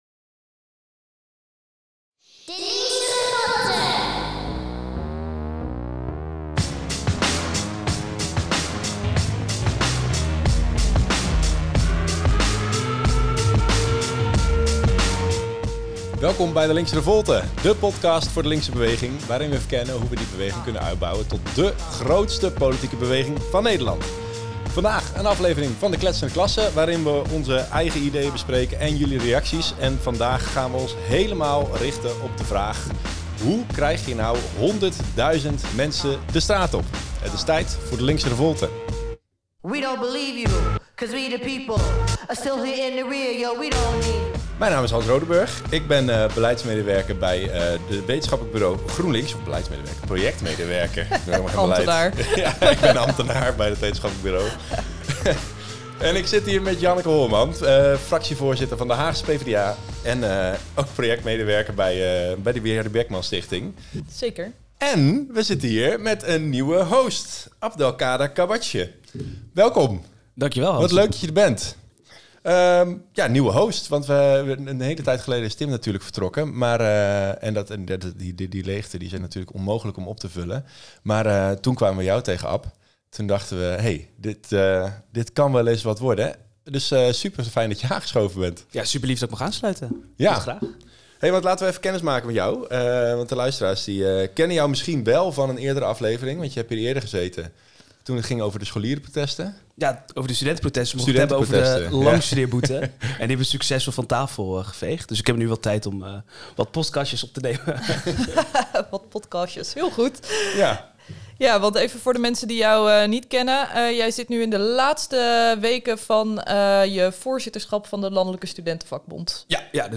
Een gesprek dat zich helemaal richt op het leren vertellen van goeie verhalen!Heb jij ook een mening over wat we zeggen of wil jij een mening inbrengen voor de volgende Kletsende Klasse?